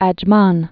(ăj-män)